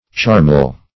Search Result for " charmel" : The Collaborative International Dictionary of English v.0.48: Charmel \Char"mel\, n. [Heb.] A fruitful field.